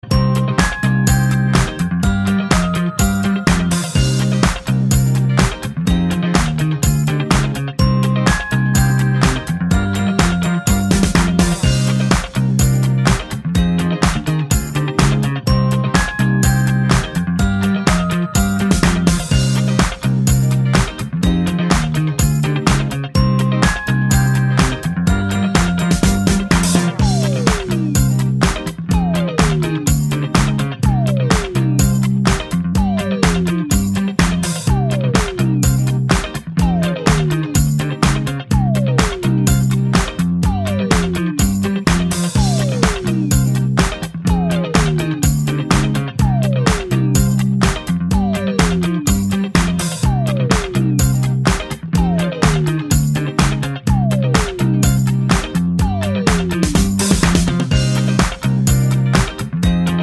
no Backing Vocals Disco 3:39 Buy £1.50